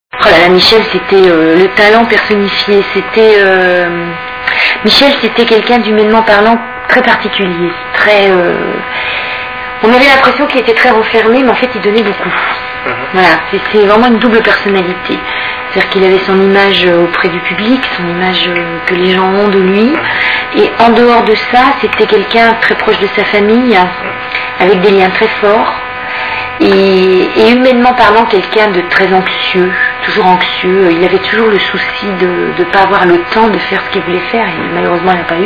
( Zénith de Lille, tournée de Roméo & Juliette, le 25/05/2001 )